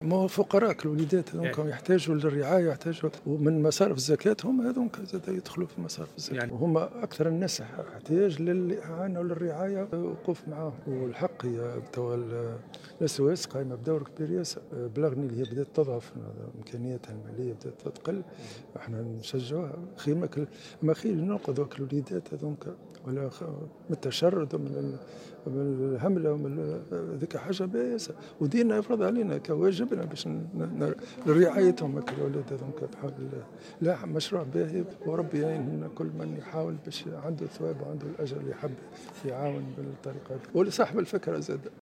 أكد مفتي الجمهورية عثمان بطيخ في تصريح لمراسل الجوهرة "اف ام" اليوم الثلاثاء على هامش مائدة إفطار الأخوة الإنسانية أنه يجوز توجيه زكاة الفطر إلى مراكز رعاية الأطفال" SOS".